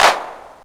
INSTCLAP01-L.wav